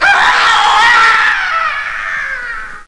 Scream Sound Effect
Download a high-quality scream sound effect.
scream.mp3